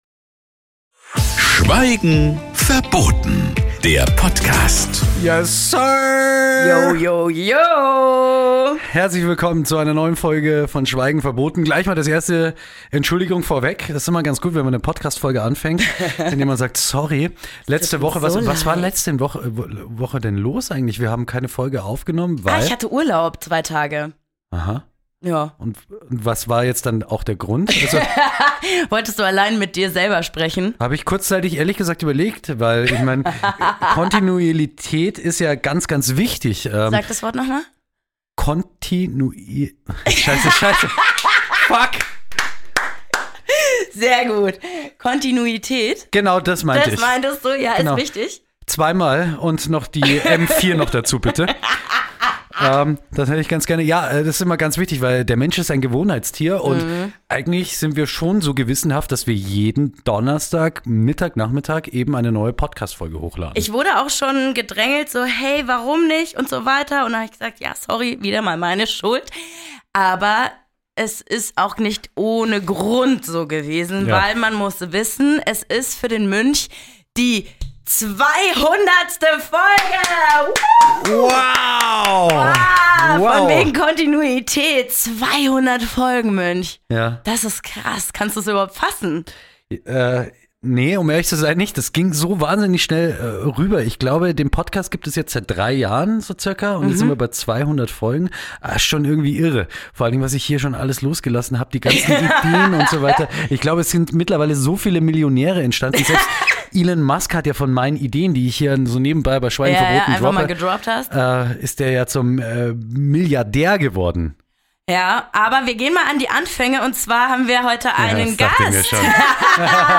Sie talken wöchentlich am Freitag so, wie ihnen der Schnabel gewachsen ist.